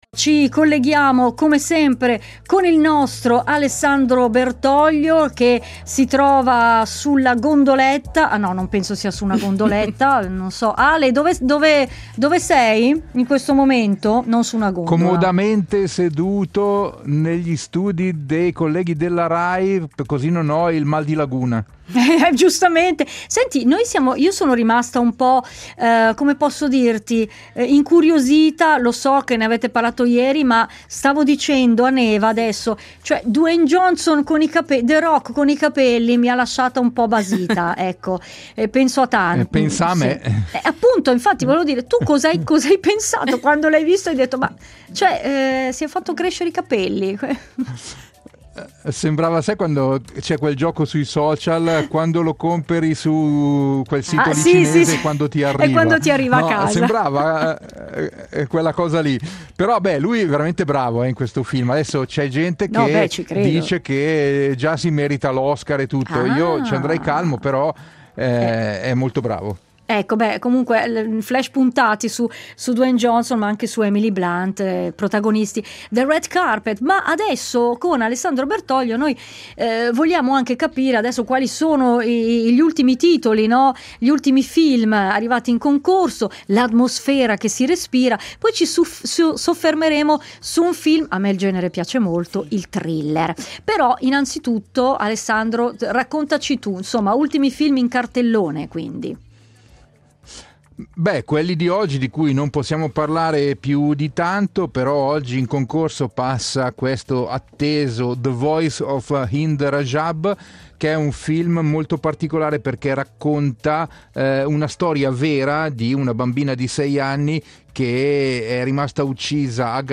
In diretta da Venezia